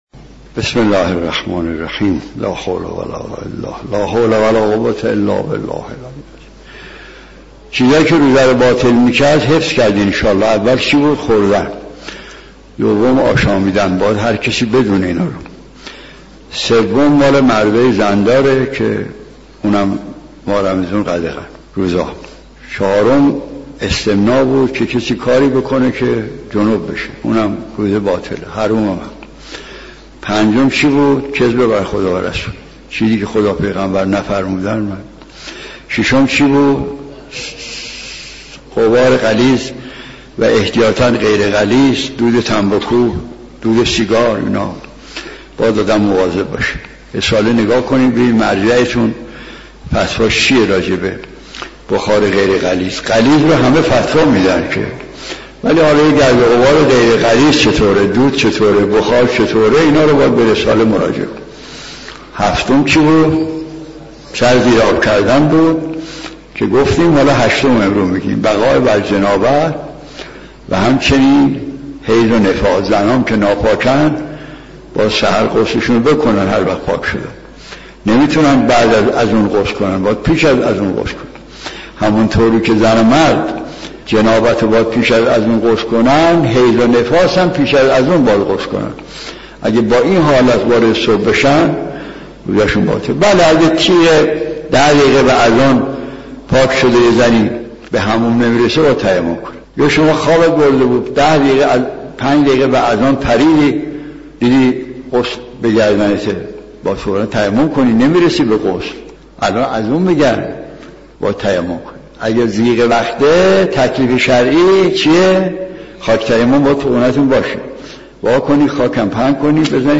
شرح دعای توسط مرحوم آیت الله مجتهدی تهرانی